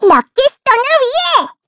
Taily-vox-get_kr_a.wav